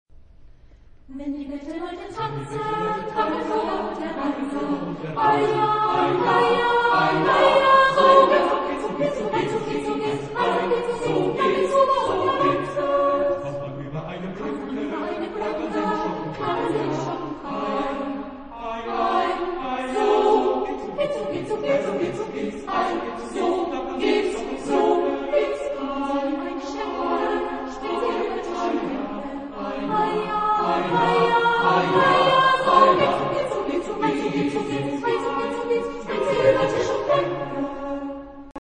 Genre-Style-Forme : Folklore ; Madrigal ; Profane
Type de choeur : SATB  (4 voix mixtes )
Tonalité : ré majeur
interprété par Chorus Cantemus Naumburg
Réf. discographique : 7. Deutscher Chorwettbewerb 2006 Kiel